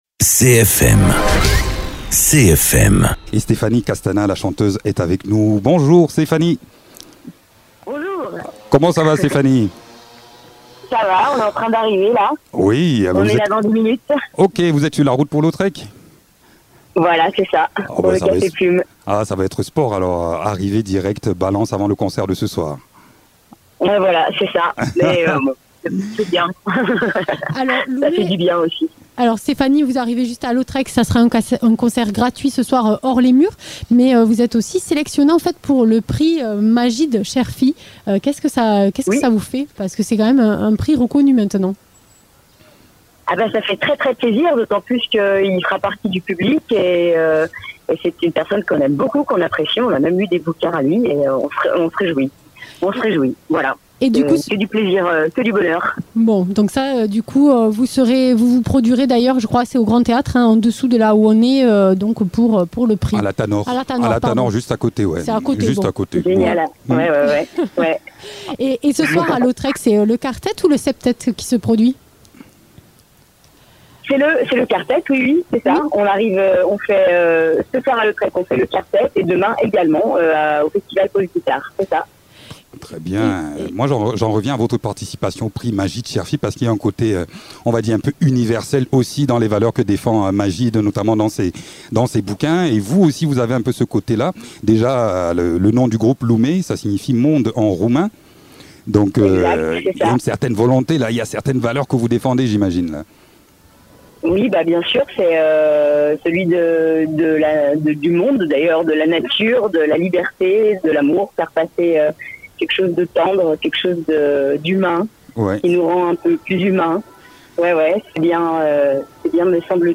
chanteuse.